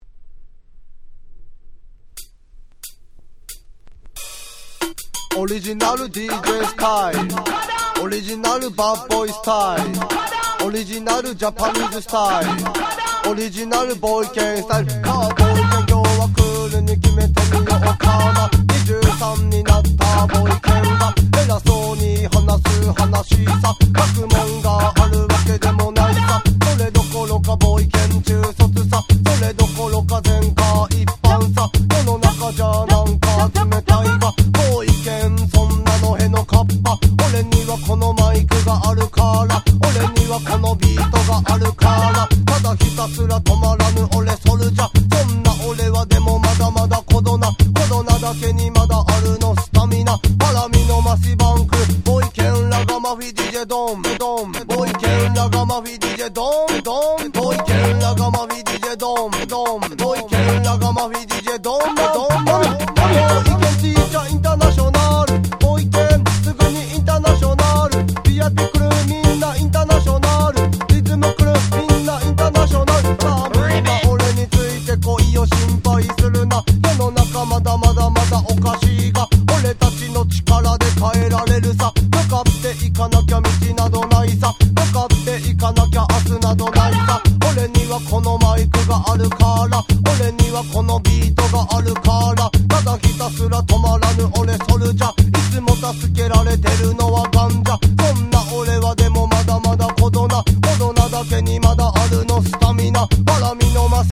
91' Japanese Reggae Classics !!